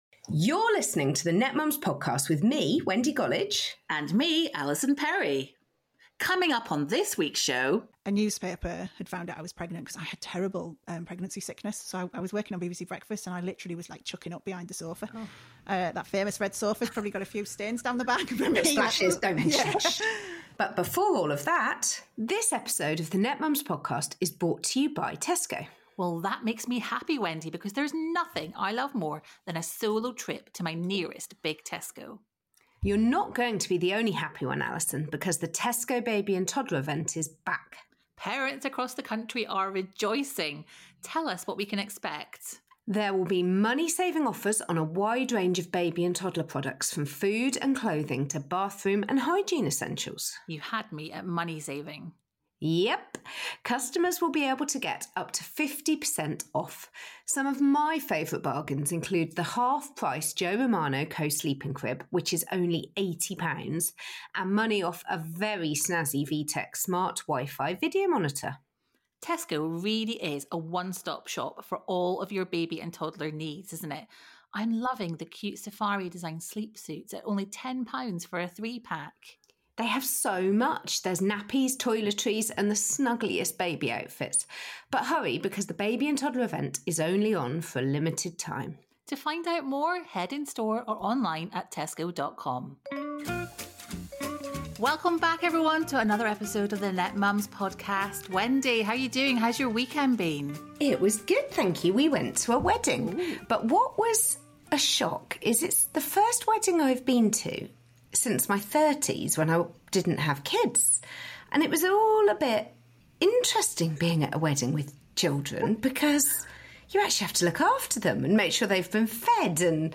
This episode of The Netmums Podcast features broadcaster, journalist and debut novelist Steph McGovern.